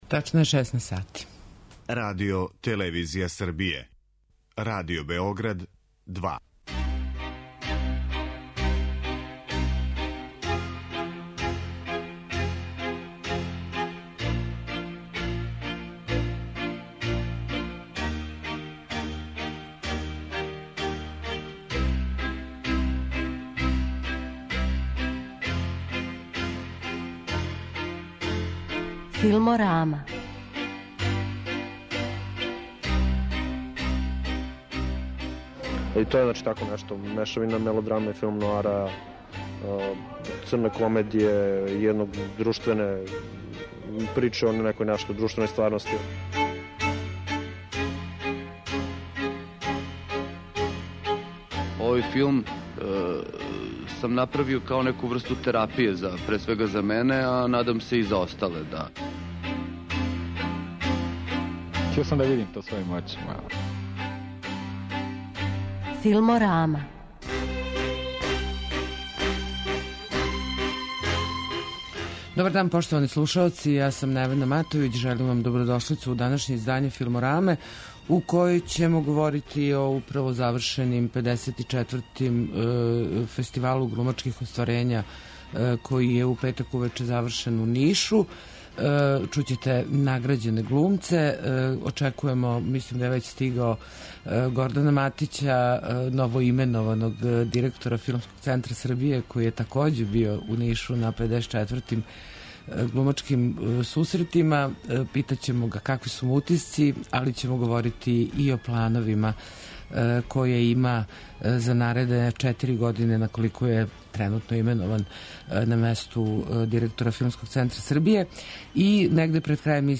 Награђене ћете чути у данашњој Филморами.